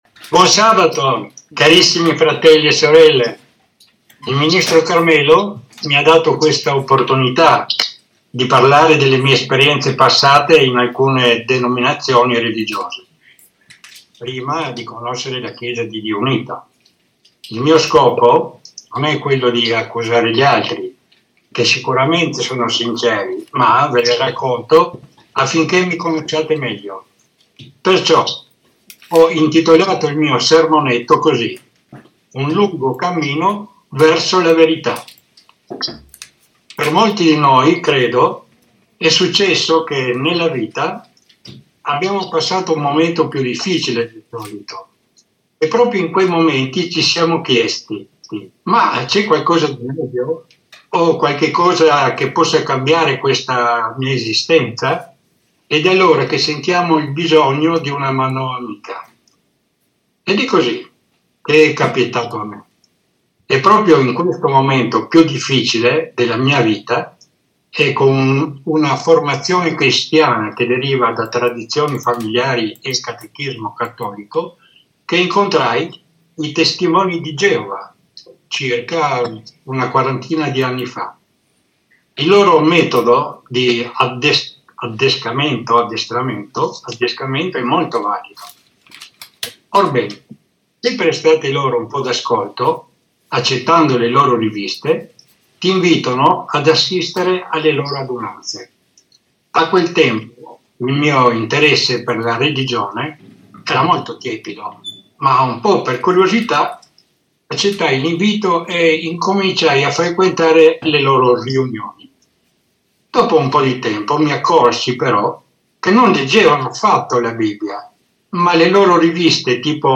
Testimonianza